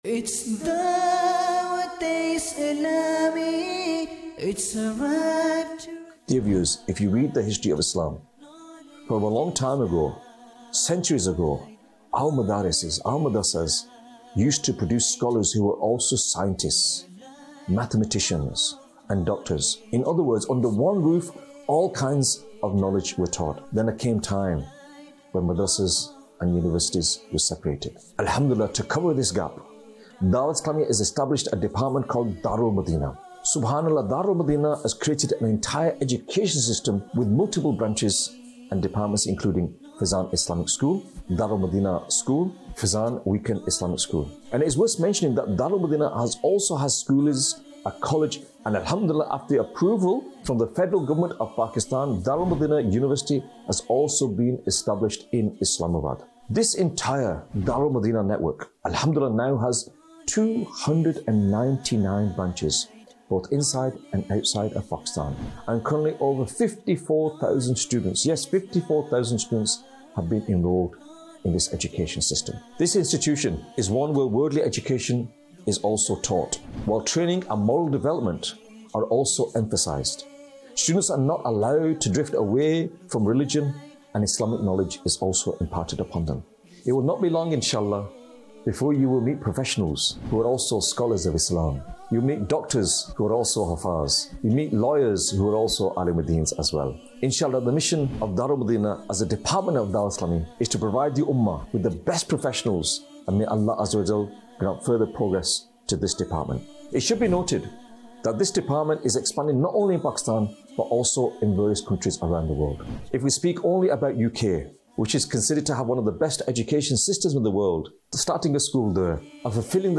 Dar-ul-Madinah | Department of Dawateislami | Documentary 2026